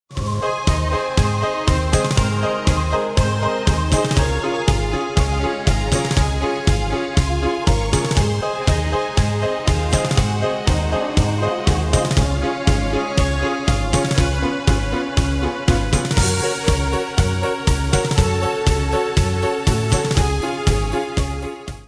Style: Retro Ane/Year: 1935 Tempo: 120 Durée/Time: 3.07
Danse/Dance: Tango Cat Id.
Pro Backing Tracks